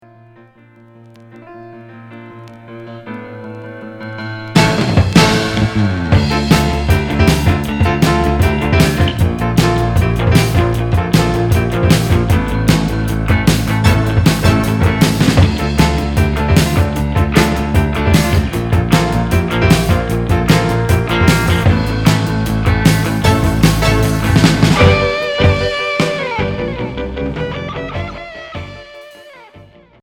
Pop progressif Unique 45t retour à l'accueil